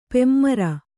♪ pemmara